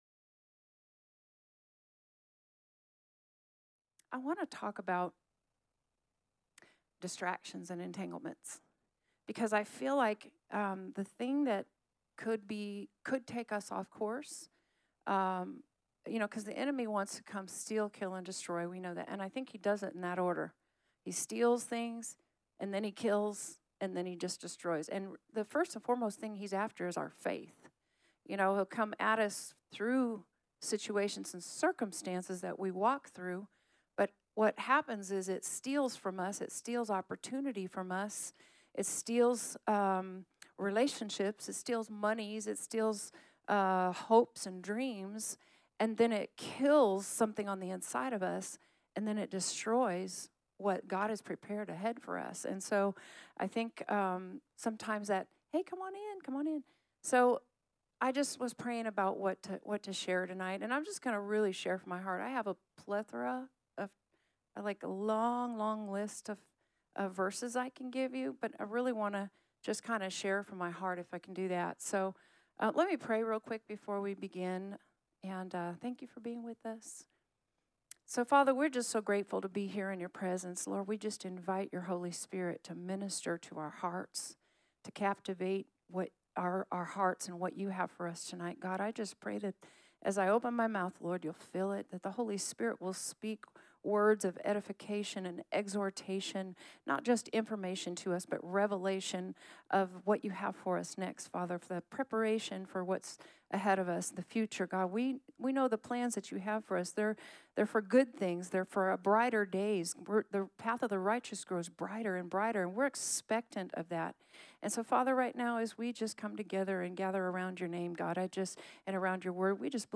Sermons | New Life Church LH